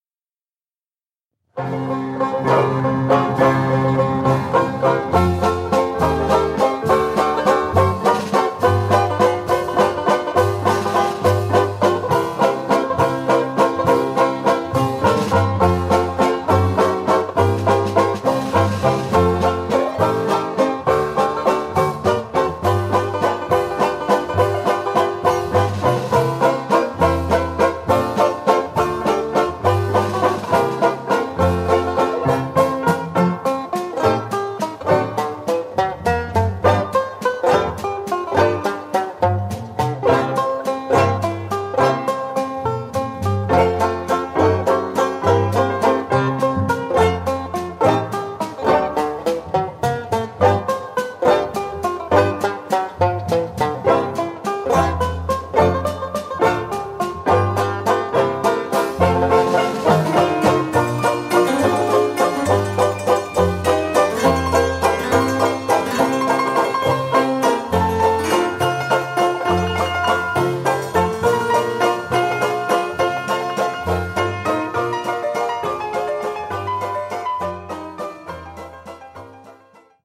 This song is in 3/4 waltz time.